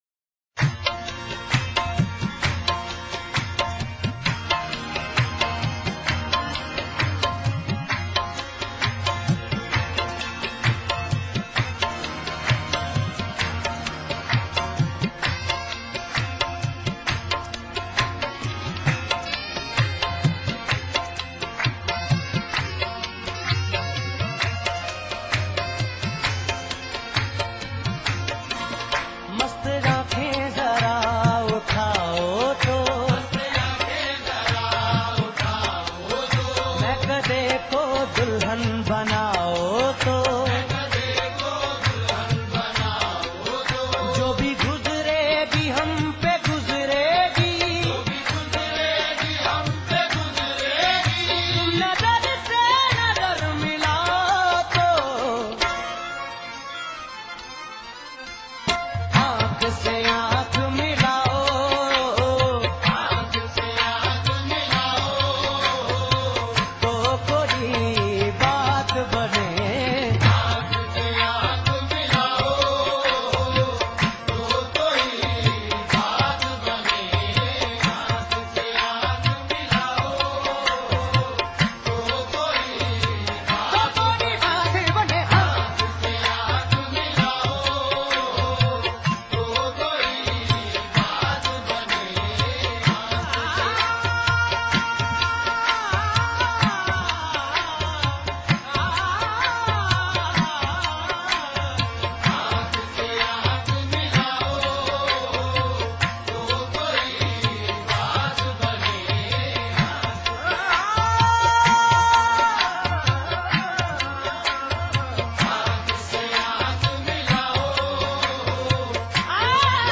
Urdu Qawwali and Sufiana Kalam